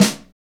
Index of /90_sSampleCDs/Northstar - Drumscapes Roland/DRM_Motown/KIT_Motown Kit2x
SNR MTWN 07R.wav